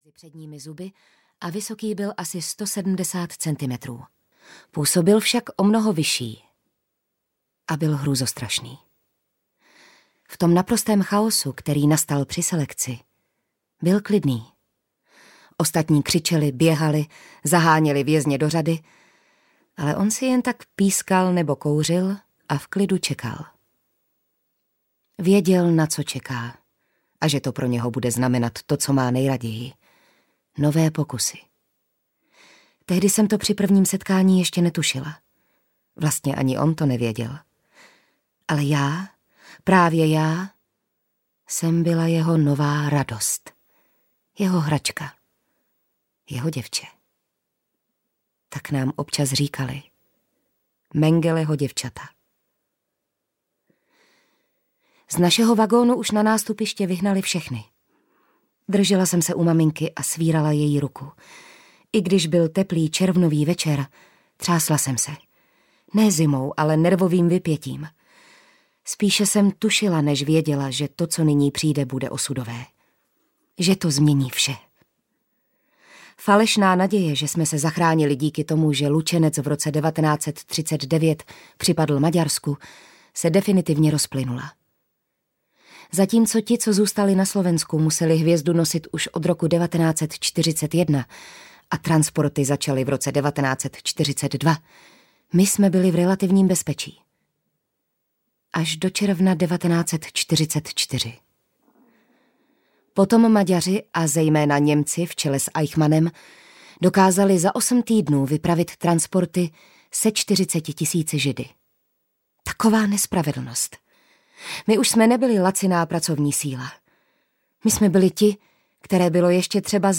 Mengeleho děvče audiokniha
Ukázka z knihy
• InterpretJitka Ježková, Vilma Cibulková